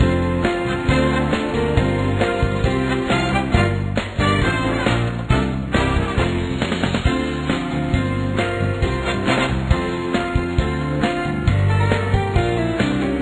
bgmusic-loop.wav